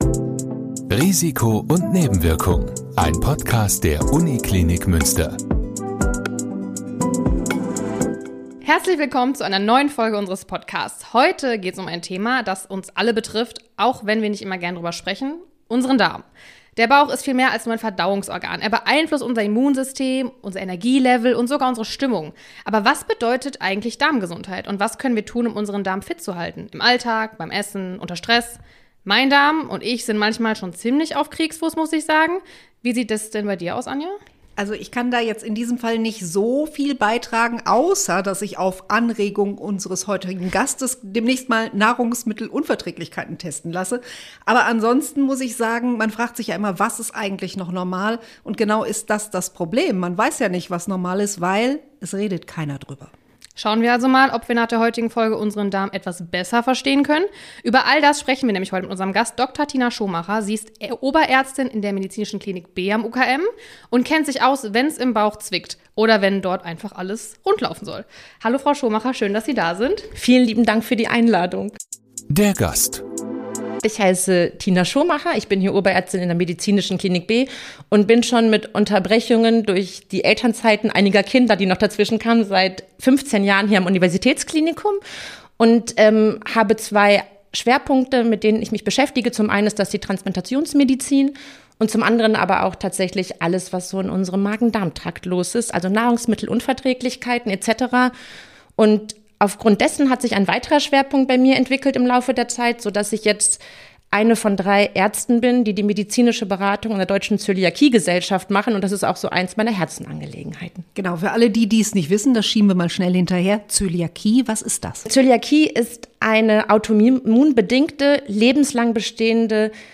In dieser Folge gibt es ein ehrliches, informatives und stellenweise sogar ziemlich unterhaltsames Gespräch über ein Organ, das viel mehr Aufmerksamkeit verdient.